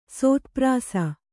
♪ sōtprāsa